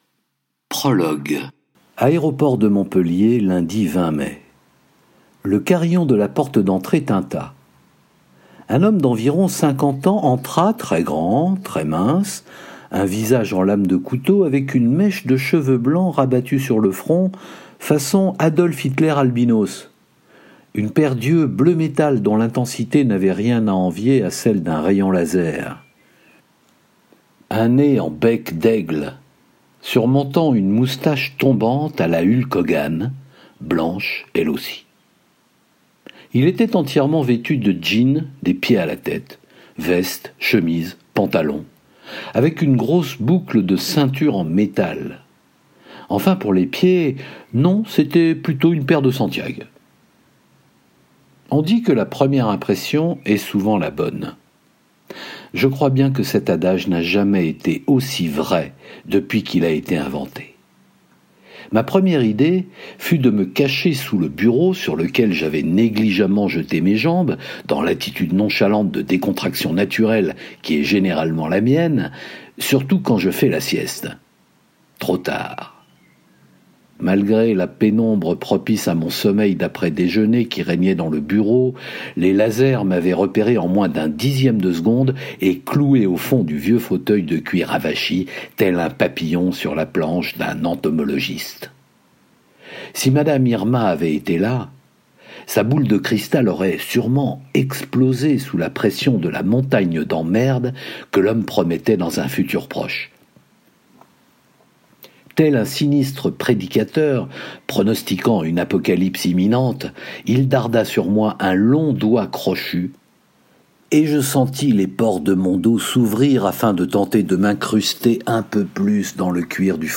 0% Extrait gratuit Disponible en ebook CHARLIE BLUES 3 , 99 € Charlie Blues Badass Boy de Frédéric Zumbiehl Éditeur : M+ Livre audio Paru le : 12/06/2020 Charlie Blues, c’est 300 pages bourrées d’action, de suspense, d’érotisme et d’humour !